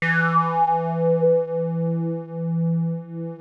JUP 8 E4 6.wav